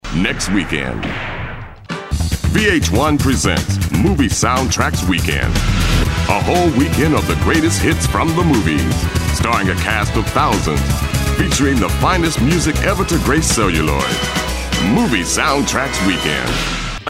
Promo, Upbeat, Hard, Shouting